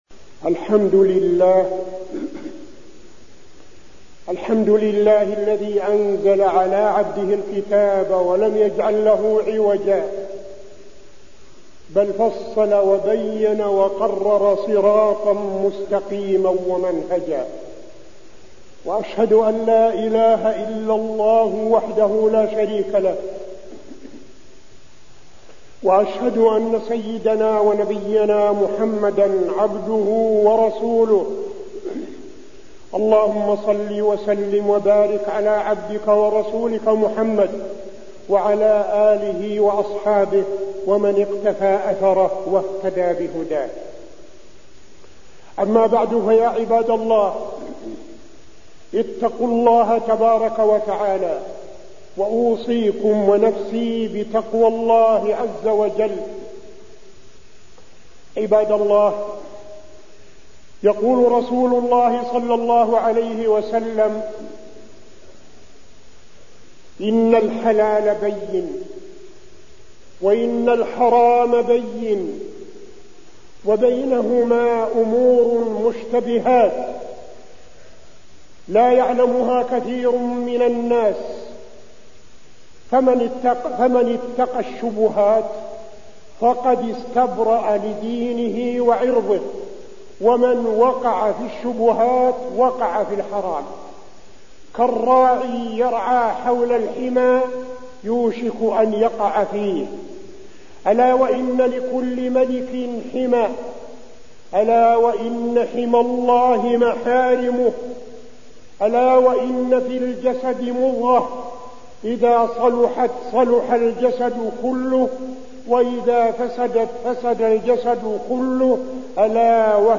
تاريخ النشر ١٠ جمادى الآخرة ١٤٠٨ هـ المكان: المسجد النبوي الشيخ: فضيلة الشيخ عبدالعزيز بن صالح فضيلة الشيخ عبدالعزيز بن صالح الحلال بين والحرم بين